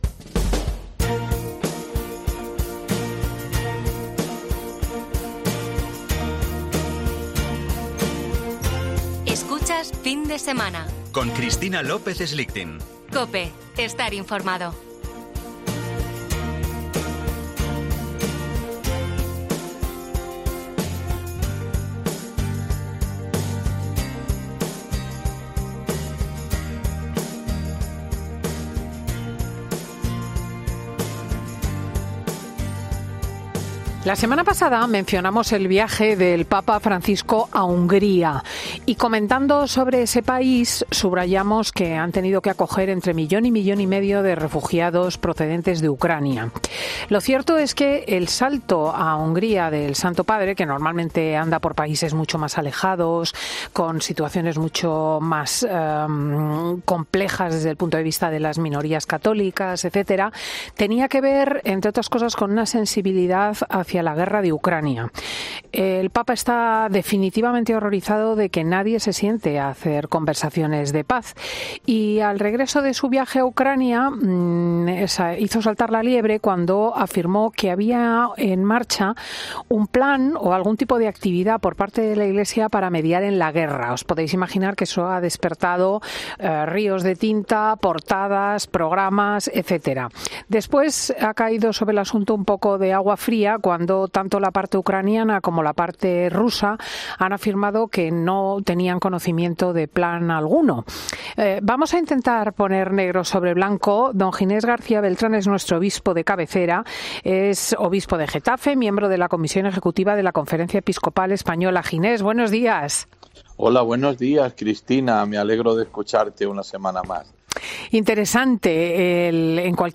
Monseñor Ginés García Beltrán es el obispo de cabecera de COPE. Aborda las palabras del Papa tras su viaje a Hungría sobre el conflicto bélico en Ucrania